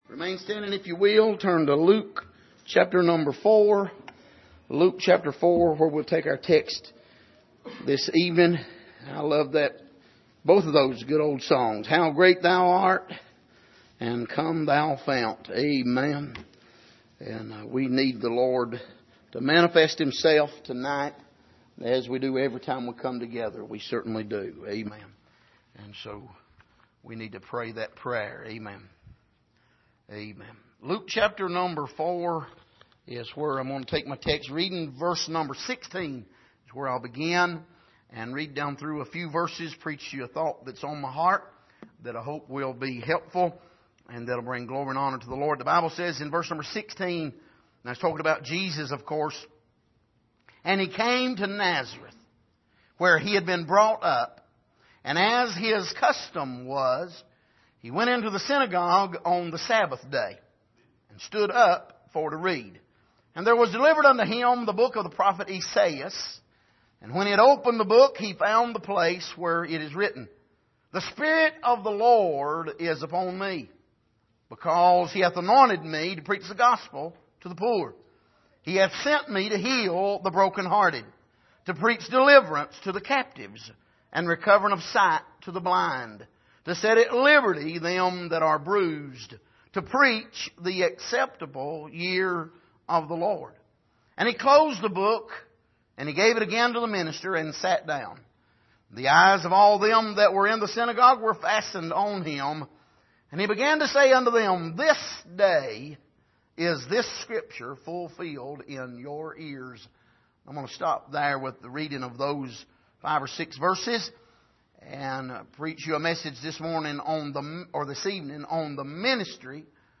Passage: Luke 4:16-21 Service: Sunday Evening